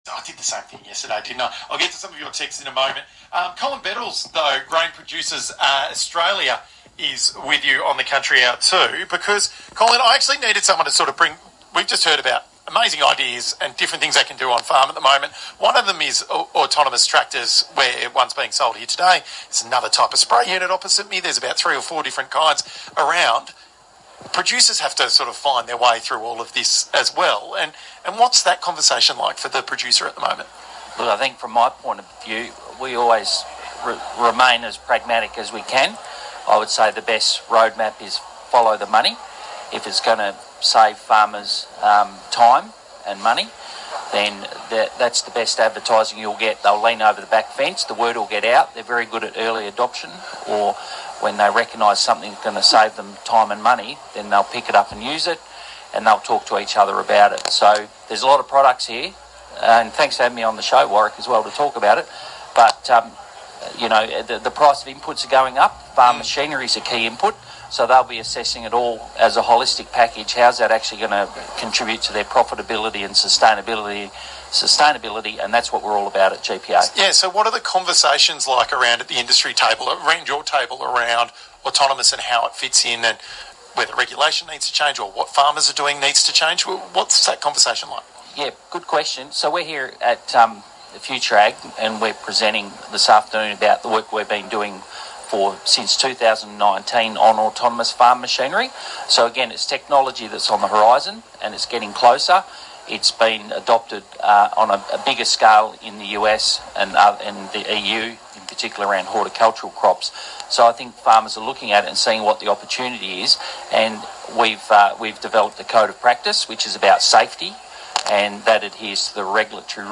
He was speaking at the FutureAg Expo held in Melbourne this week.